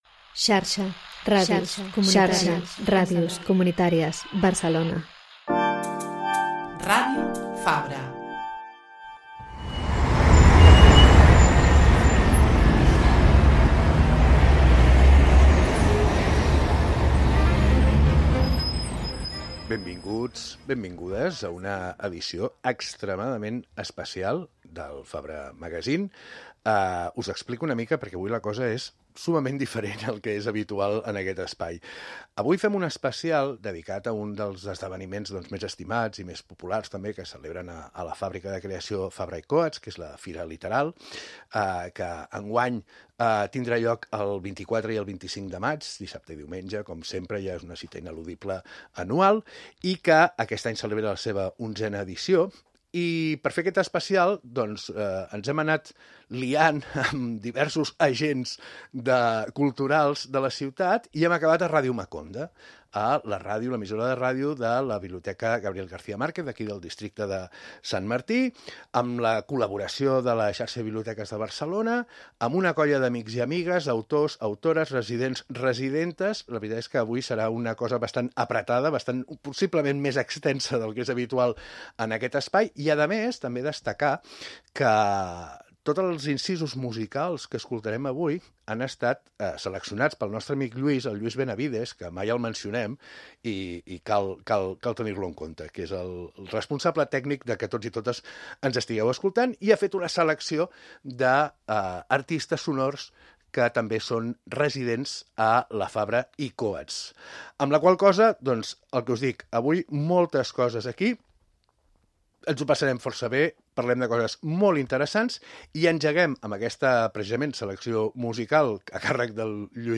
El Fabra Magazine surt de l’estudi de Ràdio Fabra per enregistrar, a Ràdio Maconda, a la Biblioteca García Márquez, un pòdcast especial sobre la Literal, la fira d’idees I llibres radicals que tindrà lloc els dies 24 i 25 de maig de 2025 a la Fàbrica de Creació de la Fabra i Coats (Barcelona).
També sentim algunes de les propostes musicals dels residents de la Fàbrica.